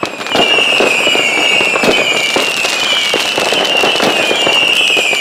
FIREWORKS 3.wav